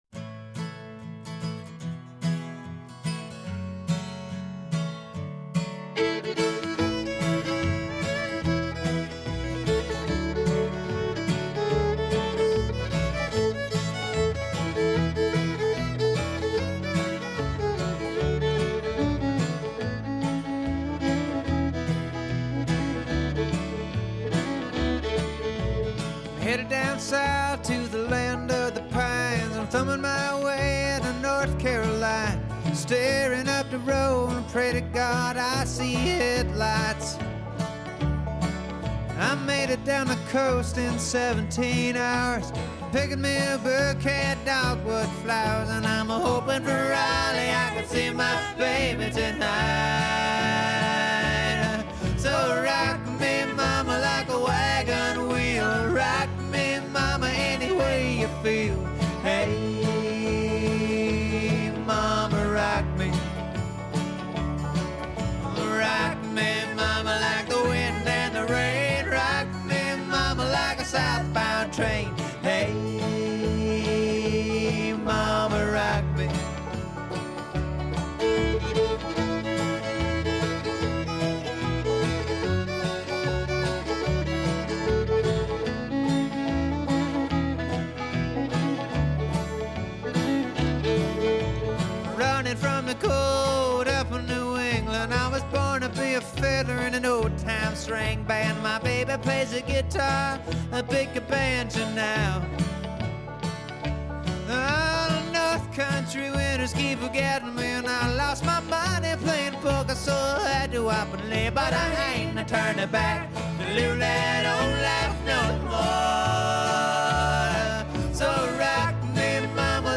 Banjo: Open G, Capo 2 Guitare: Capo 2